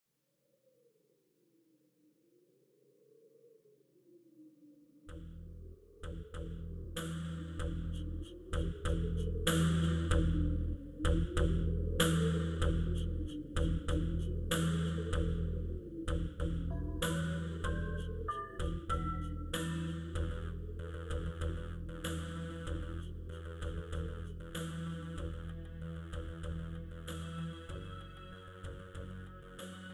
This is an instrumental backing track cover.
• Key – E
• With Backing Vocals
• No Fade